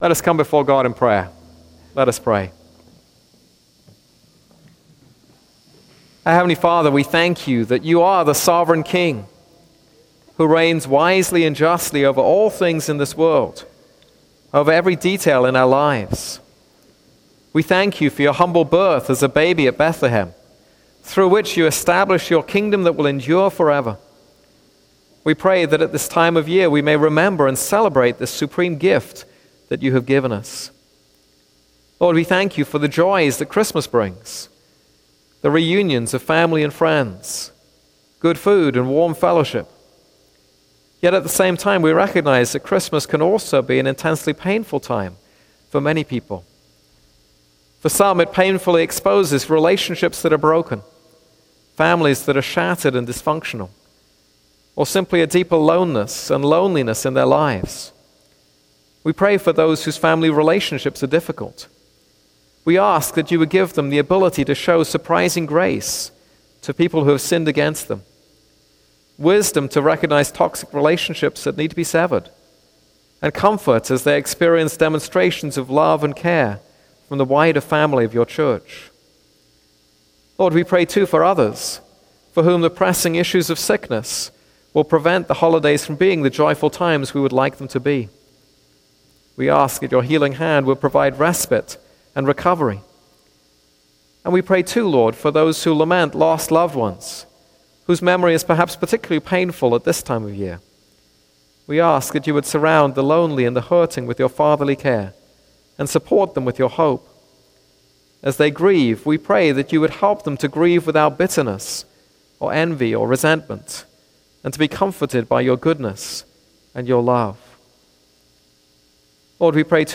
This is a sermon on Malachi 3:13-4:6.